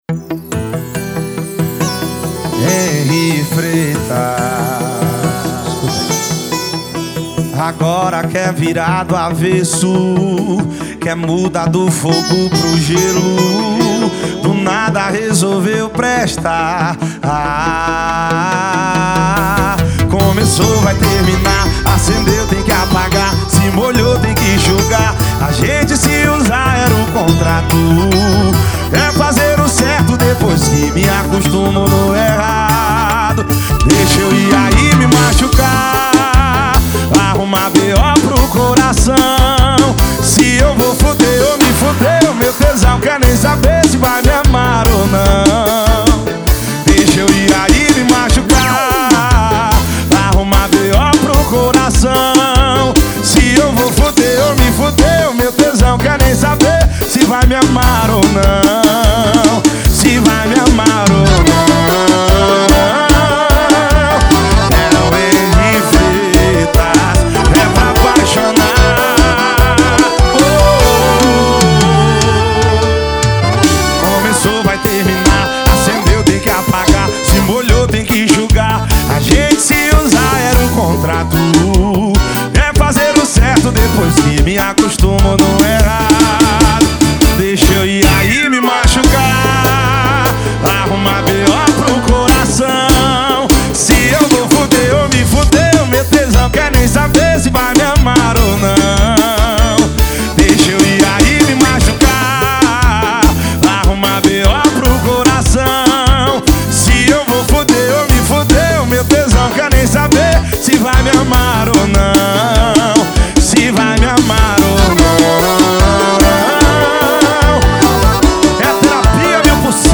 2024-02-14 18:25:23 Gênero: Forró Views